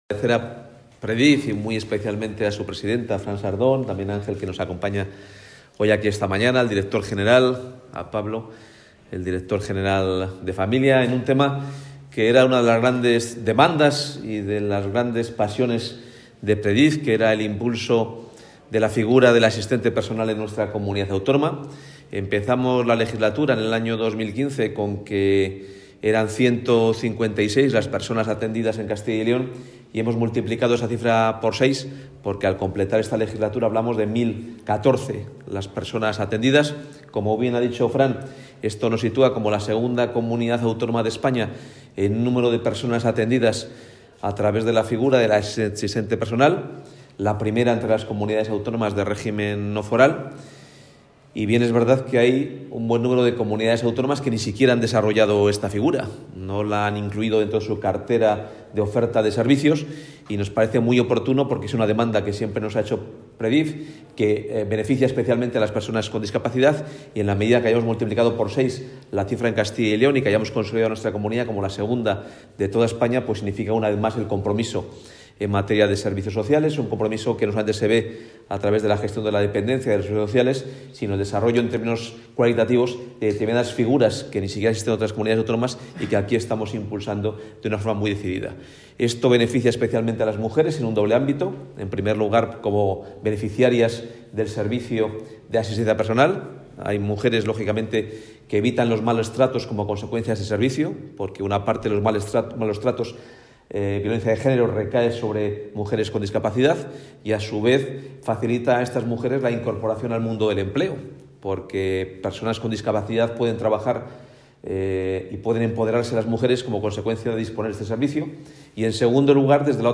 Consejero de Empleo.